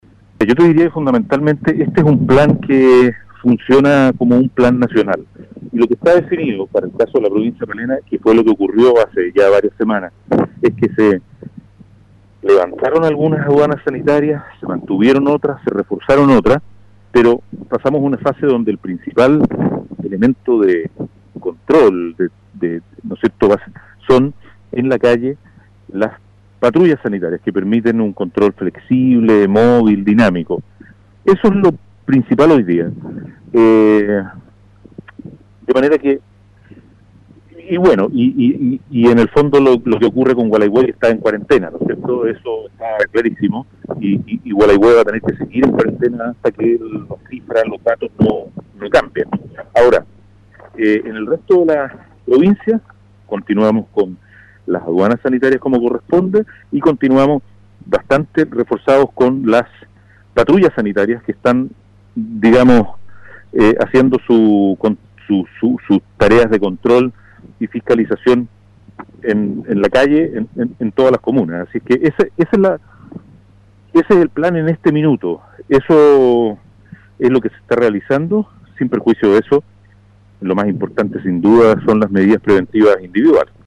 Sobre las medidas de control, prevención y fiscalización en la provincia, el gobernador Carrasco señaló que se mantienen vigentes, recalcando que todo ello no dará resultados si no hay un cuidado propio de las personas.